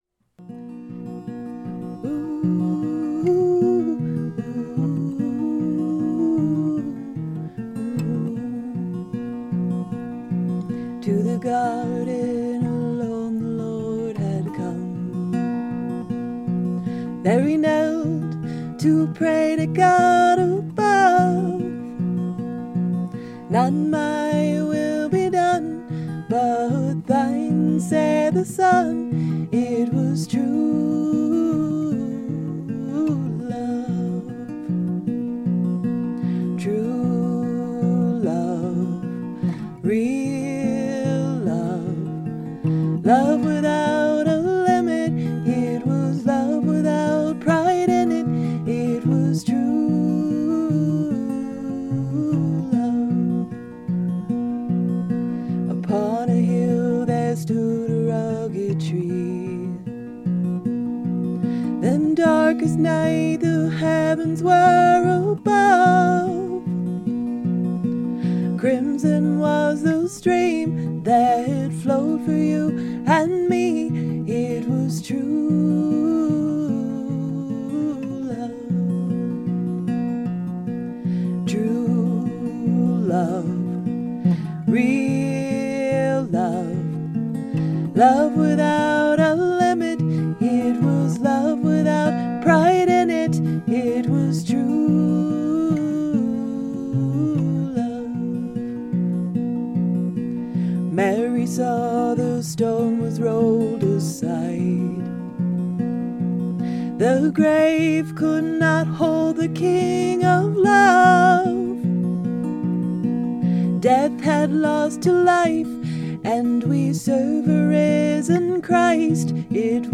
Capo 1